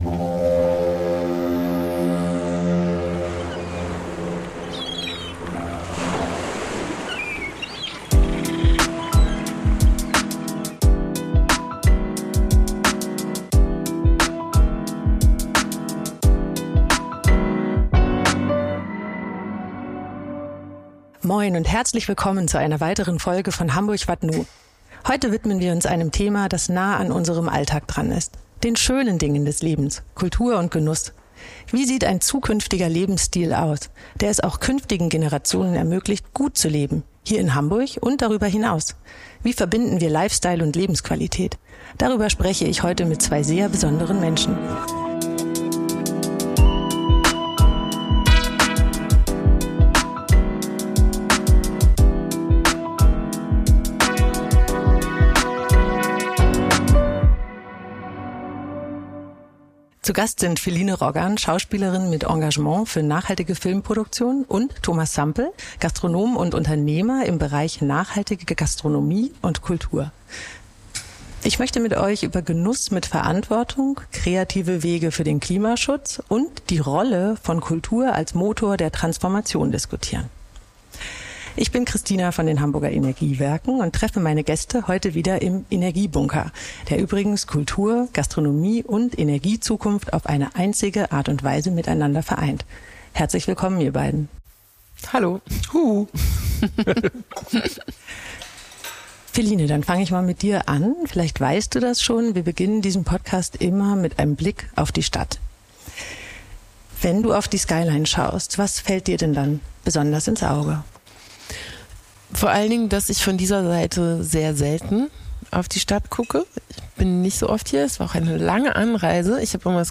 Aufgenommen im Energiebunker Wilhelmsburg – einem Ort, der Energiezukunft, Kultur und Gastronomie verbindet.